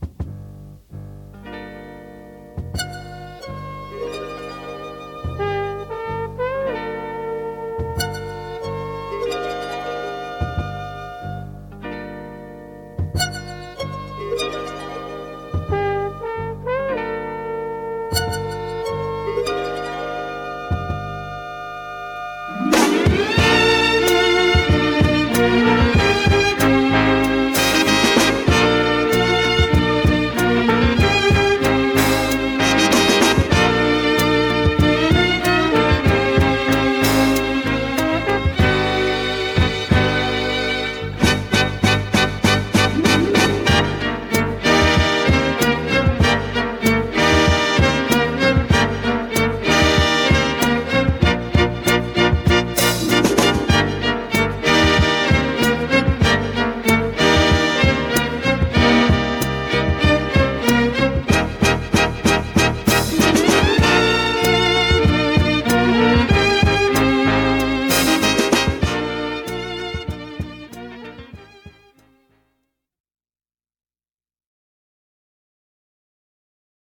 Mp3 demos of a few below.(Wav files will be better quality)